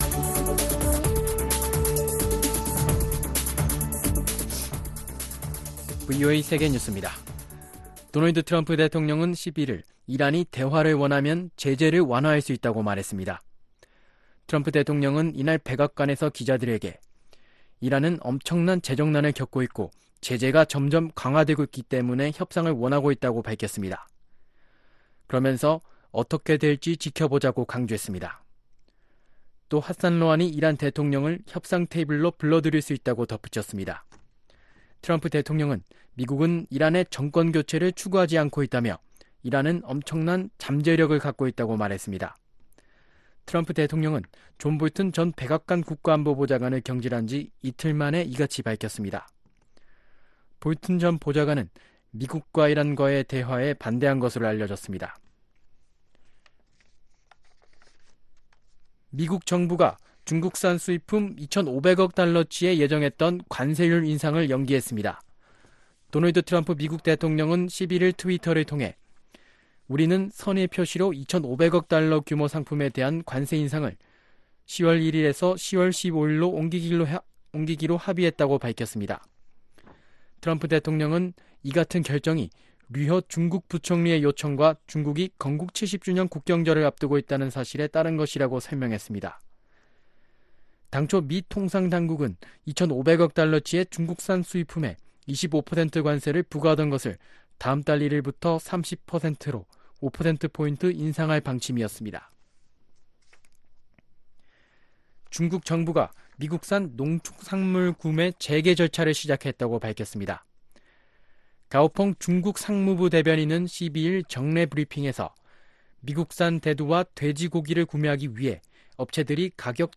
VOA 한국어 아침 뉴스 프로그램 '워싱턴 뉴스 광장' 2019년 9월 13일 방송입니다. 도널드 트럼프 대통령이 존 볼튼 전 백악관 국가안보보좌관 경질에 대해, 북 핵 문제과 관련해 ‘리비아 모델’을 언급한 것은 볼튼 전 보좌관의 큰 실수 였다고 지적했습니다. 미국 민주당 대통령 후보 경선에 나선 지지율 상위권 후보들은 모두 김정은 위원장을 직접 만날 의향을 밝혔습니다.